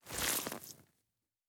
added stepping sounds
Wet_Snow_Mono_04.wav